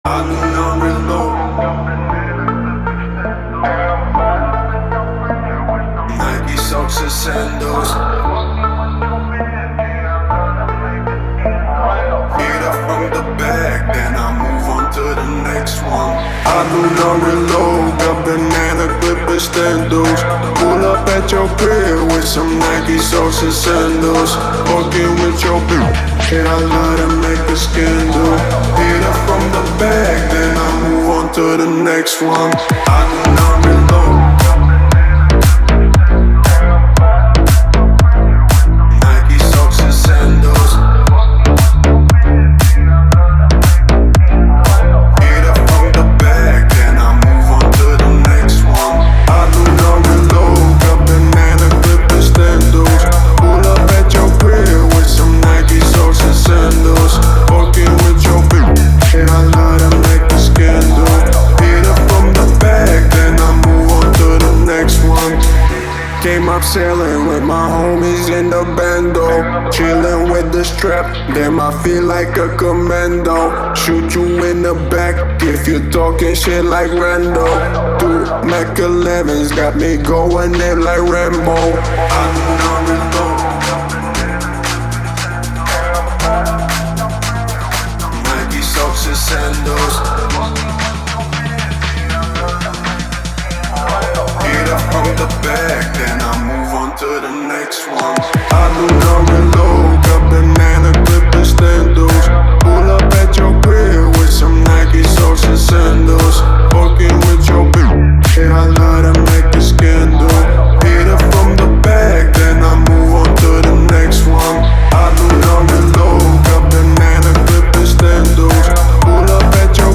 мощными битами и запоминающимися мелодиями
который умело сочетает рэп и мелодичные элементы.